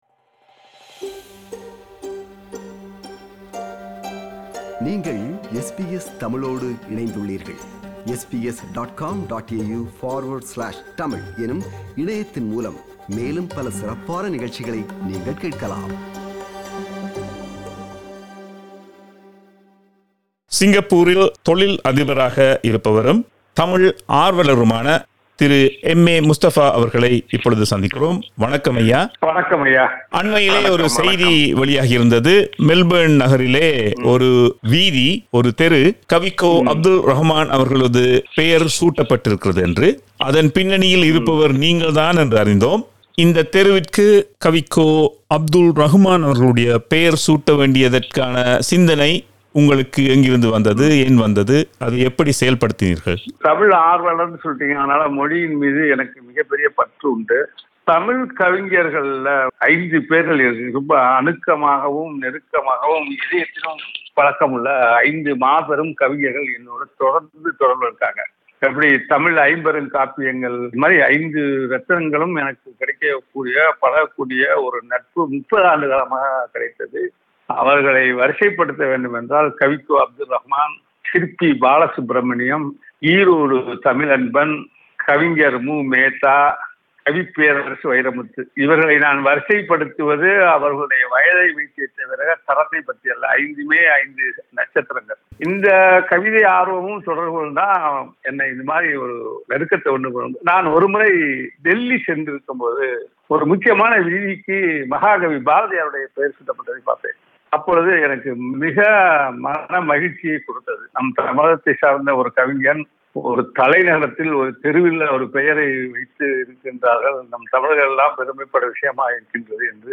This is the first of the two-part interview.